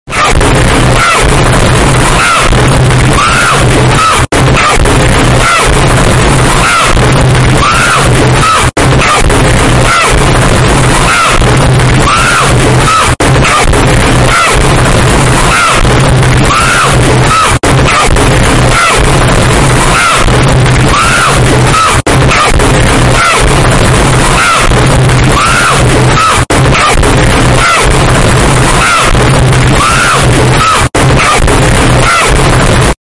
FALLIN DOWN DA STAIRS!!